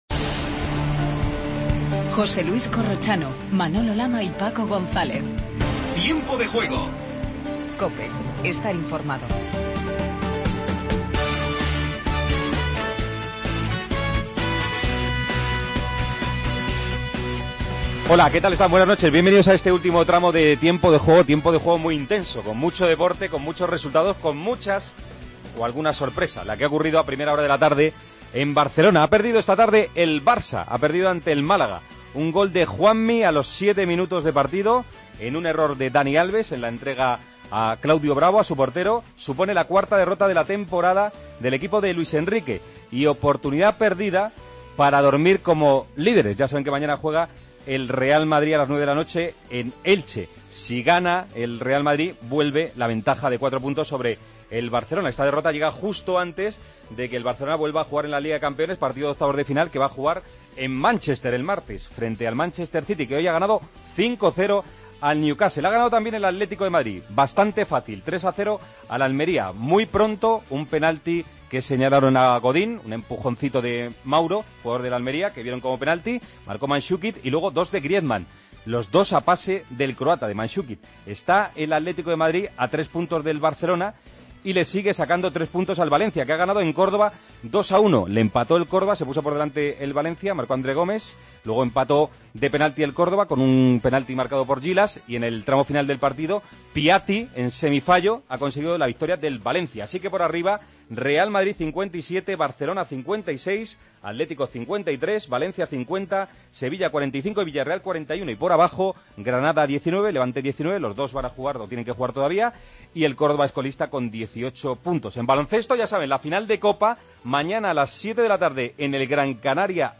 Entrevistas a los protagonistas del derbi gallego, Charles, Víctor Fernández y el 'Toto' Berizzo. Además, en Las Palmas charlamos con el base del Real Madrid, Sergio Llull.